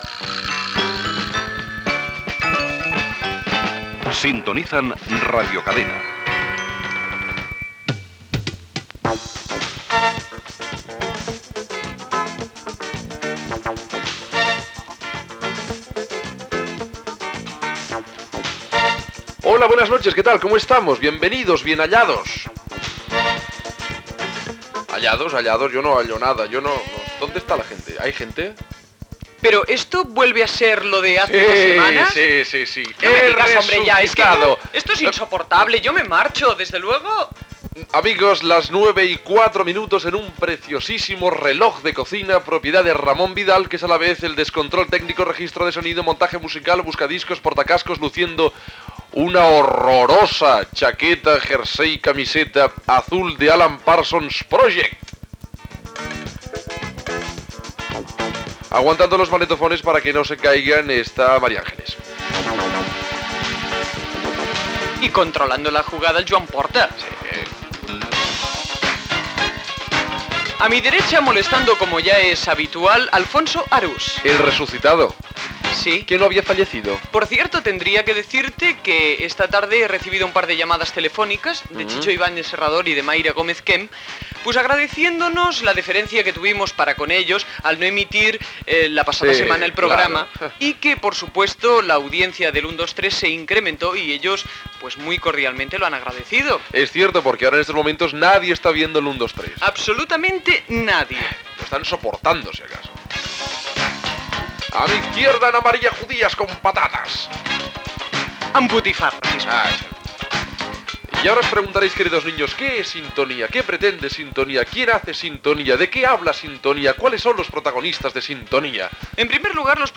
Espai d'humor sobre els professionals de RCE a Barcelona.
Entreteniment
FM